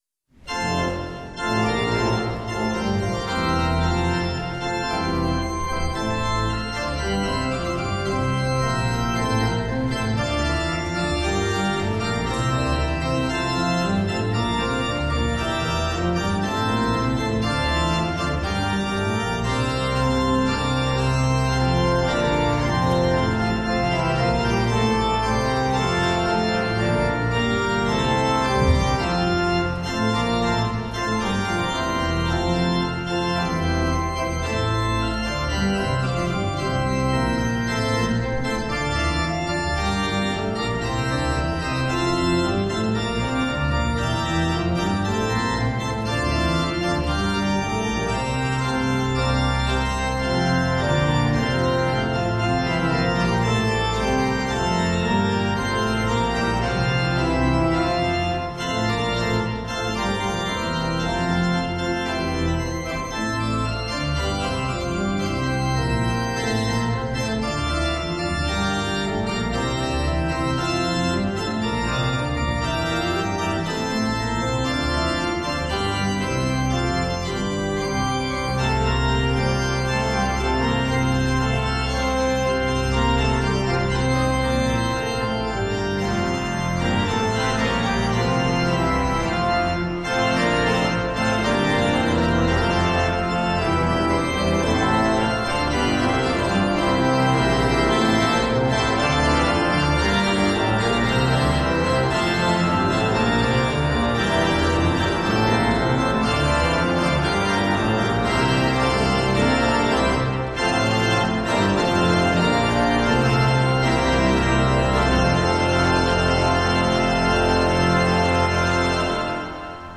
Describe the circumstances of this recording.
Hear the Bible Study from St. Paul's Lutheran Church in Des Peres, MO, from December 22, 2024. Join the pastors and people of St. Paul’s Lutheran Church in Des Peres, MO, for weekly Bible study on Sunday mornings.